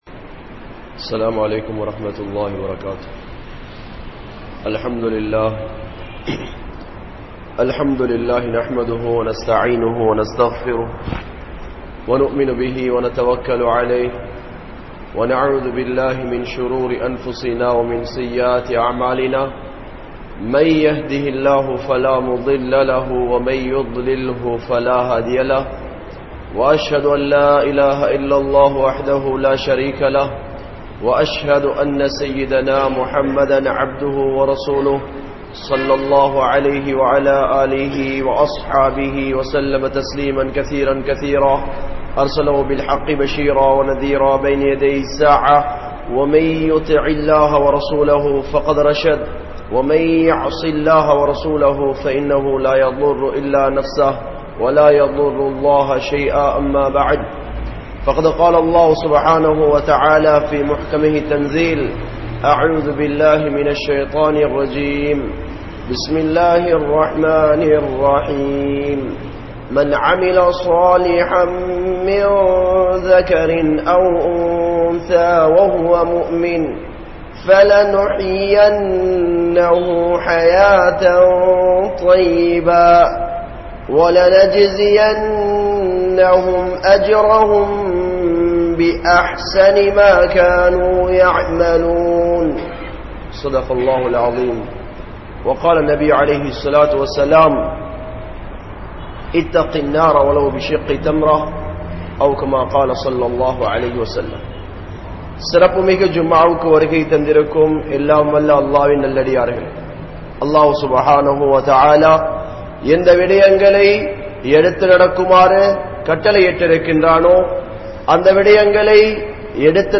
Allah`vin Uthavi Veanduma? (அல்லாஹ்வின் உதவி வேண்டுமா?) | Audio Bayans | All Ceylon Muslim Youth Community | Addalaichenai